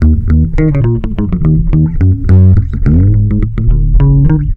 RI BASS 1 -R.wav